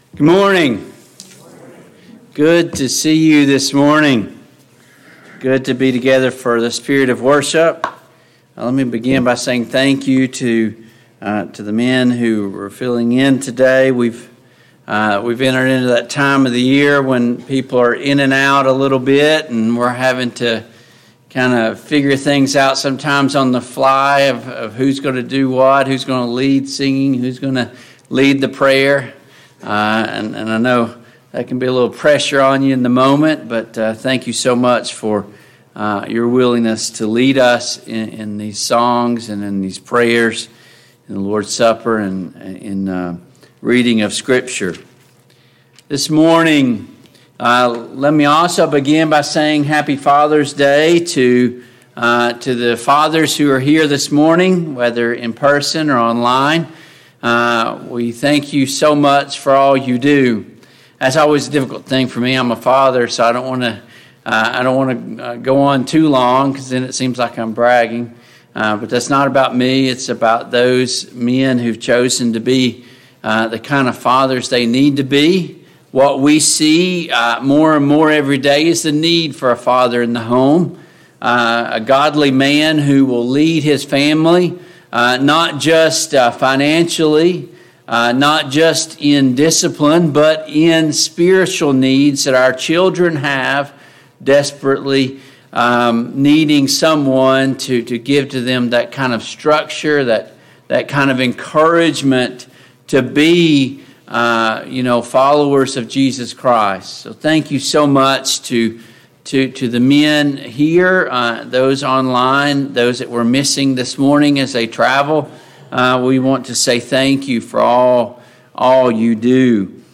Service Type: AM Worship Download Files Notes Topics: Evangelism , Personal Evangelism « Science and the Bible 4.